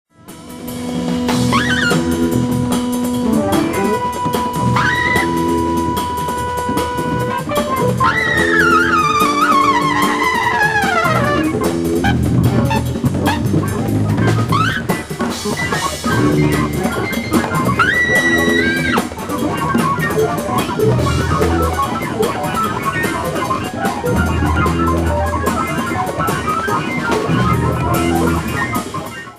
LIVE AT DE DOELEN HALLE, ROTTERDAM 10/29/1971
SOUNDBOARD RECORDING